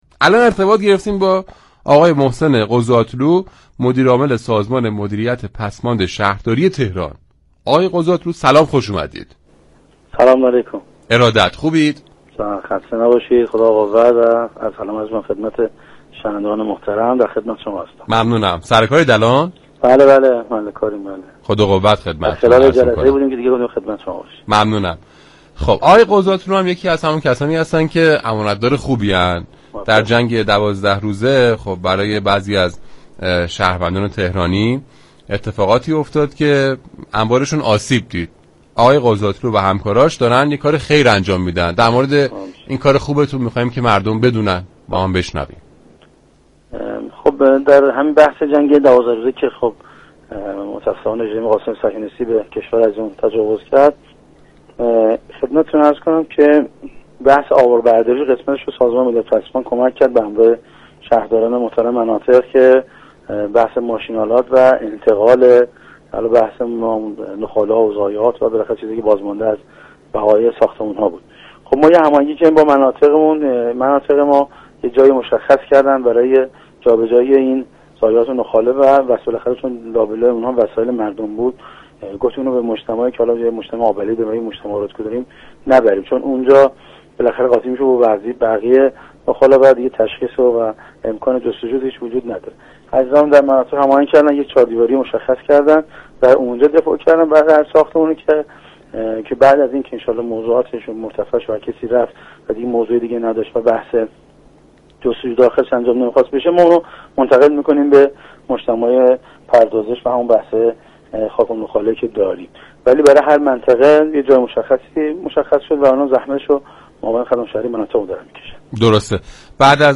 به گزارش روابط عمومی رادیو صبا ،در برنامه «نت زندگی»، ارتباطی با محسن قضاتلو، مدیرعامل سازمان مدیریت پسماند شهرداری تهران برقرار شد.